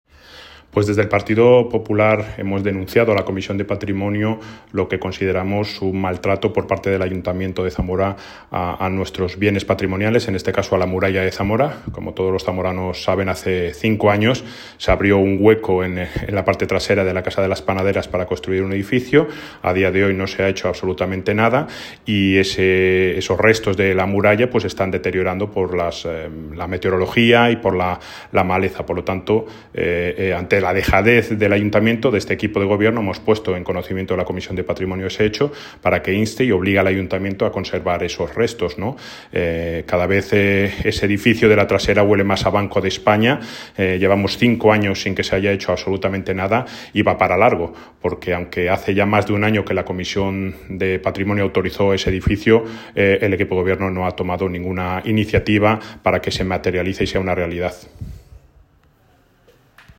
Declaraciones de Jesús María Prada, Portavoz Municipal del Partido Popular